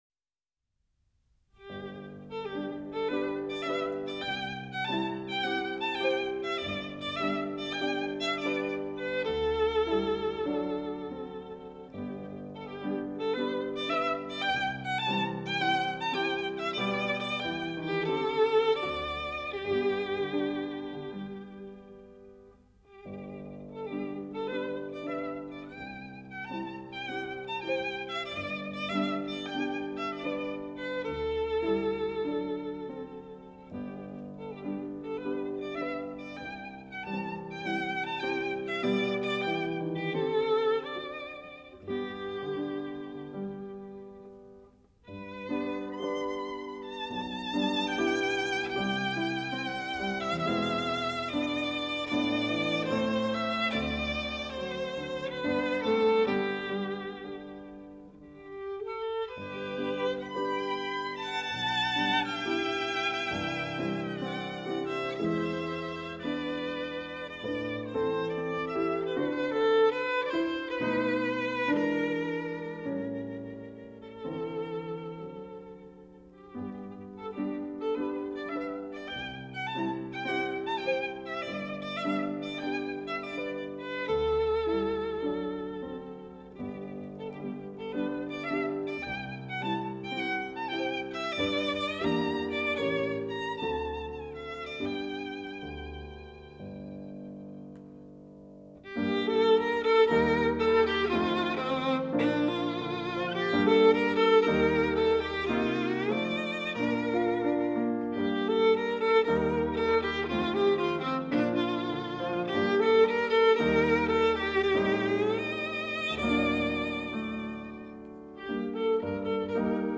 音效超群，因此特别推荐可以将其专门用做测试音响器材的发烧试机片。